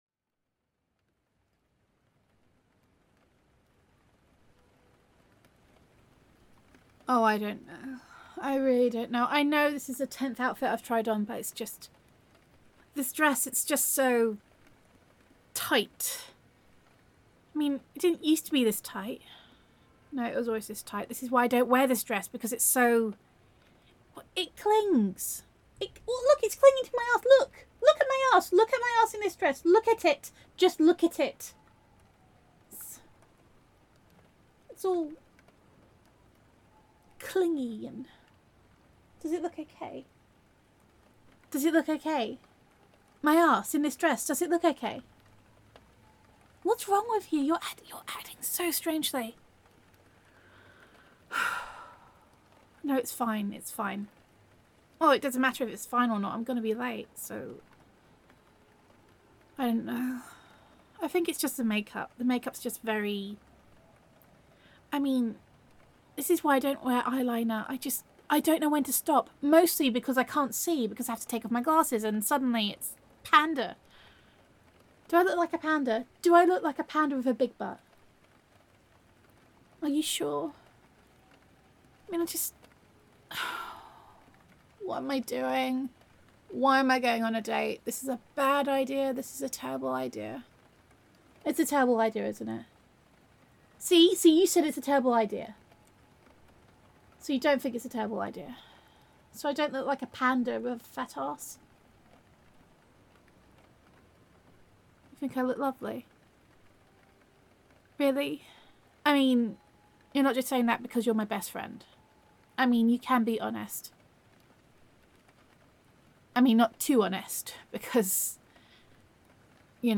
[F4A] This Is So Inconvenient [Best Friend Roleplay]